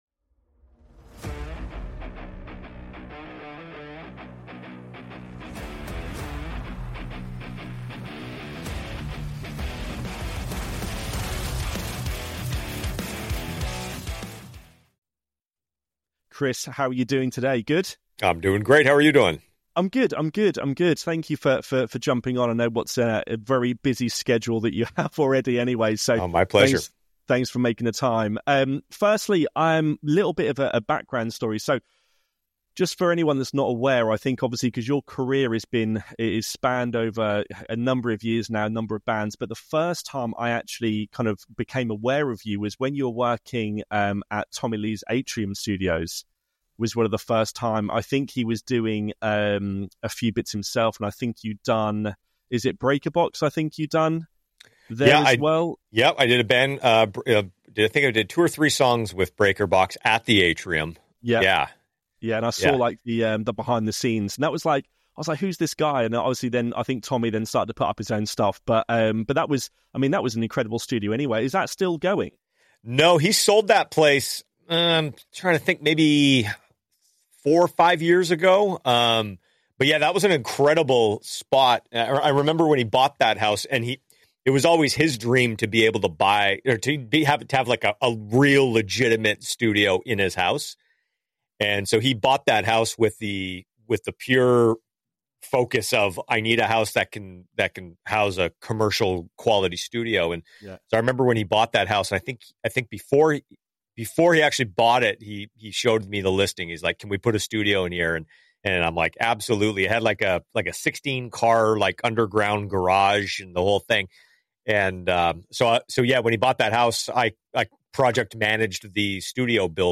"Tech Me Out" is a deep dive into the world of live music production, featuring candid conversations with the very people who ensure that every performance, from the smallest venues to the largest arenas, runs smoothly. These are the stories of the individuals who work tirelessly behind the scenes, sharing their experiences, challenges, and the unique insights that only come from life on the road.